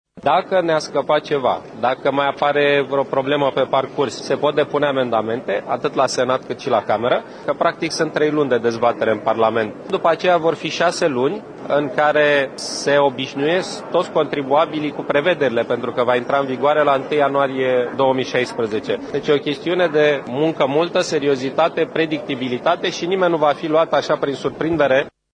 Premierul Ponta a declarat că executivul se aşteptă ca noul cod să accelereze creşterea economică și să ofere predictibilitate: